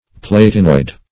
Definition of platinoid.
Search Result for " platinoid" : The Collaborative International Dictionary of English v.0.48: Platinoid \Plat"i*noid\, a. [Platinum + -oid.]